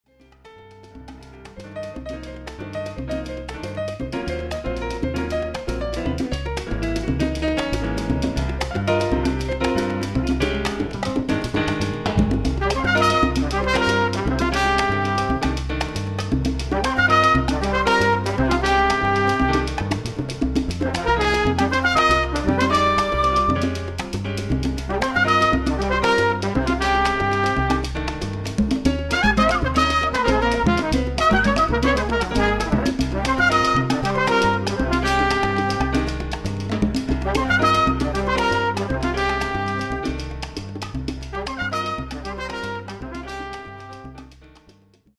Category: combo
Style: mambo